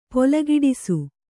♪ polagiḍisu